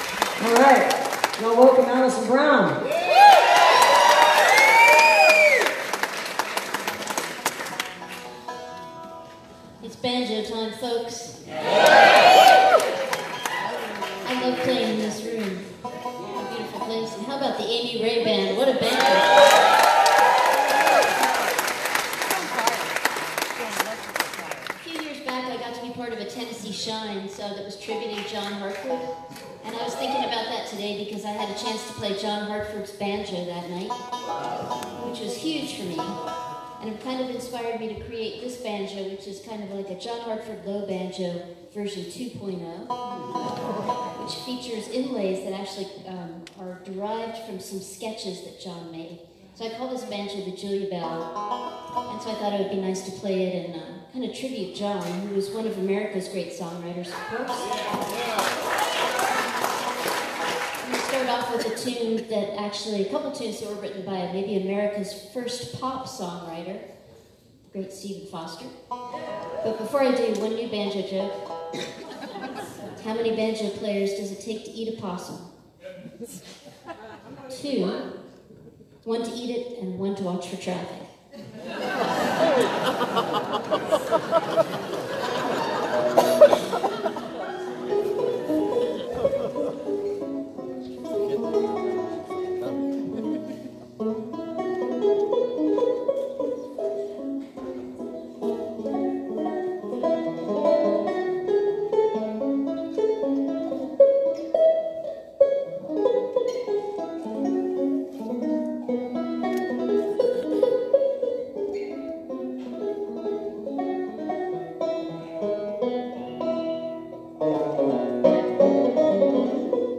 (captured from facebook live stream)